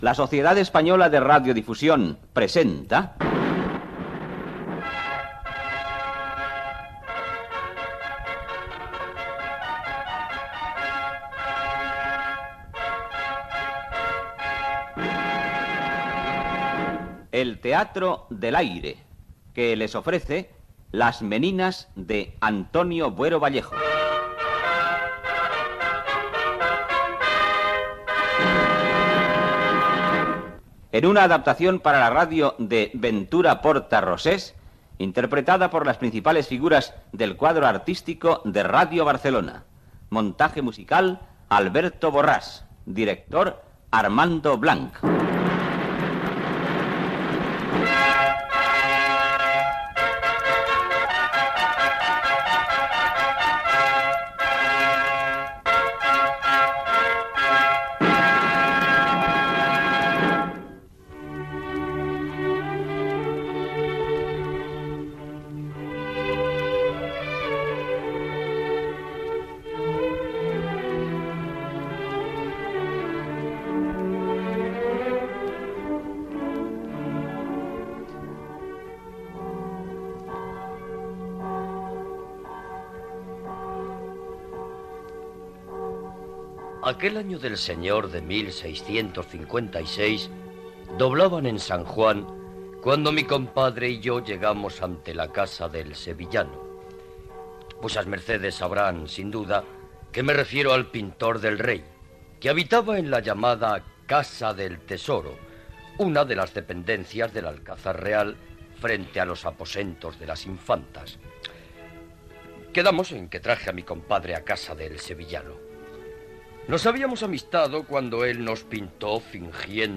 Adaptació radiofònica de "Las Meninas" d' Antonio Buero Vallejo.
Careta del programa i inici de l'obra.
Diàleg entre Juana Pacheco (també coneguda coma Juana de Miranda) i el pintor Diego Velázquez.
Ficció